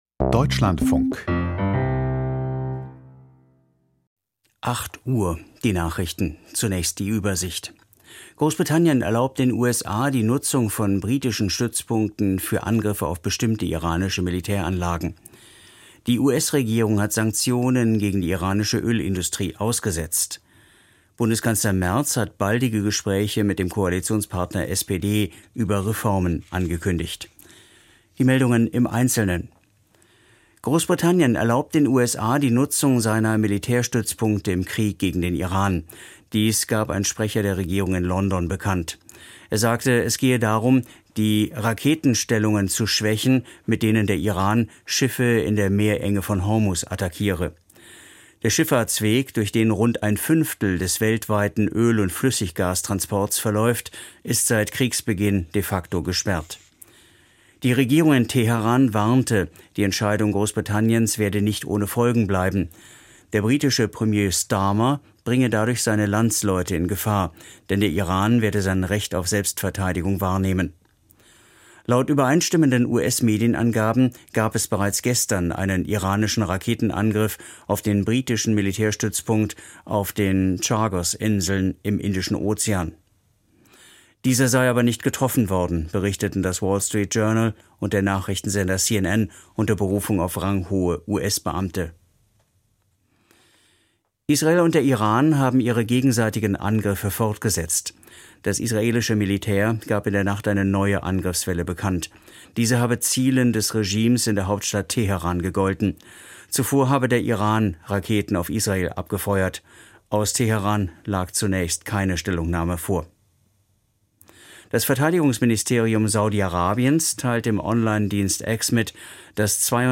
Die Nachrichten vom 21.03.2026, 08:00 Uhr
Aus der Deutschlandfunk-Nachrichtenredaktion.